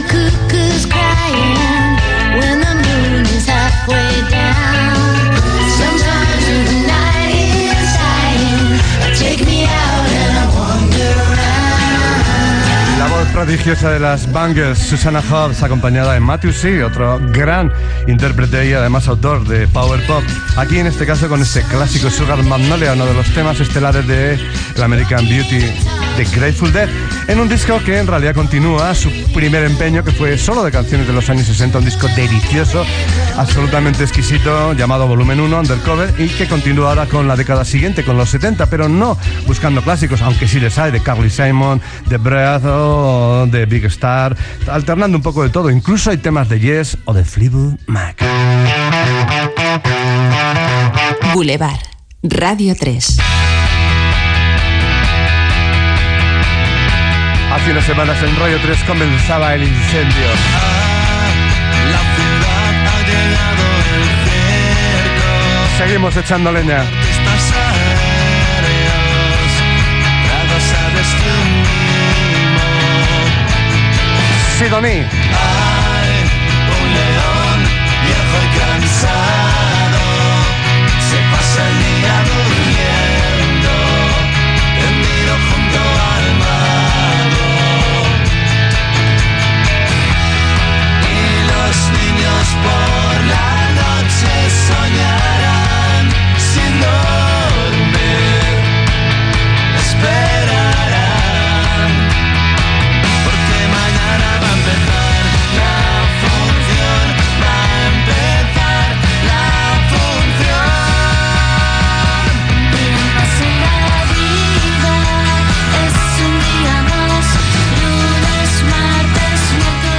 Tema musical, comentari del disc, indicatiu i presentació d'un tena musical i comentari posterior
Musical